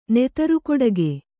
♪ netaru koḍage